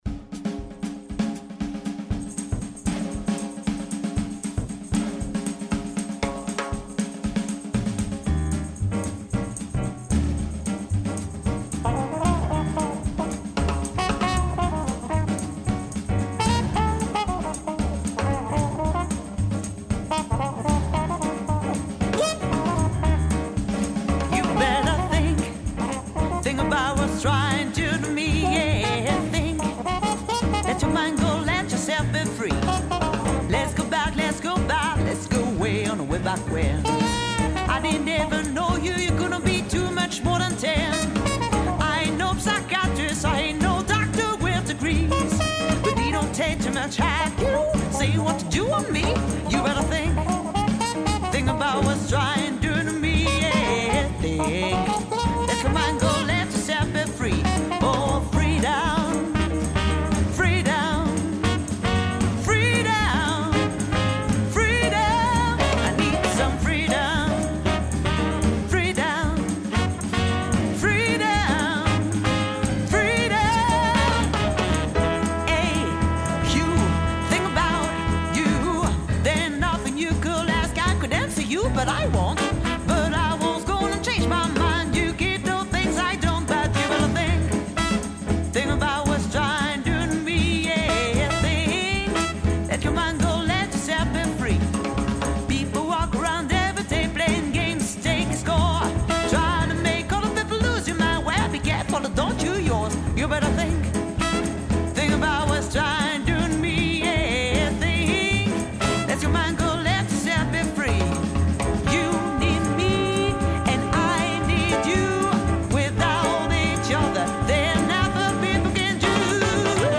le sextet qui l'accompagne.
chant
trompette
saxophone ténor
trombone
piano, clavier
contrebasse
batterie